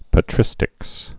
(pə-trĭstĭks)